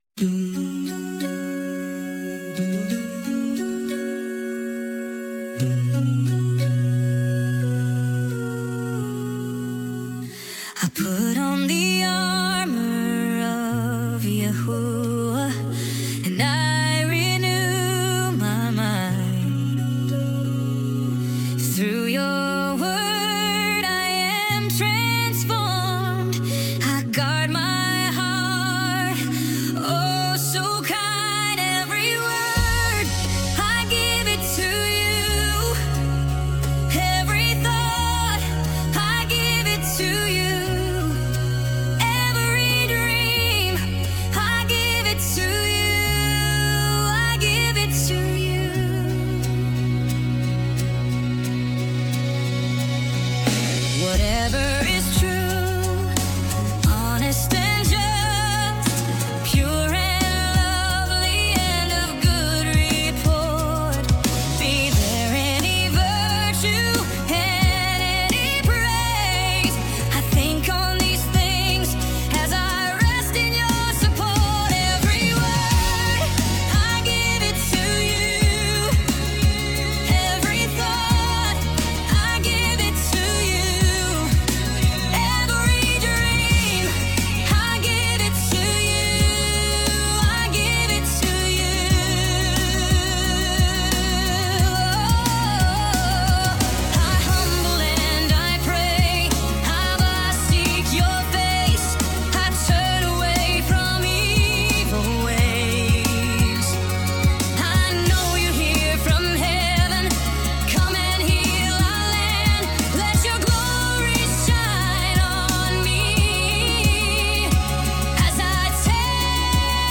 Original worship music including
• [ Practice Track – Higher Key ]